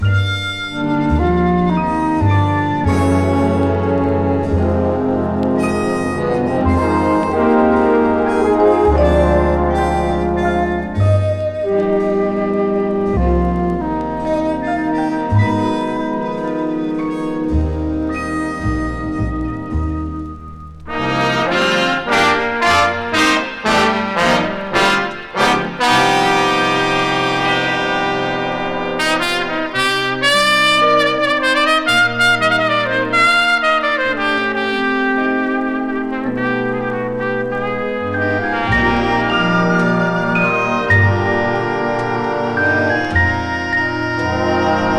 Jazz, Big Band　USA　12inchレコード　33rpm　Mono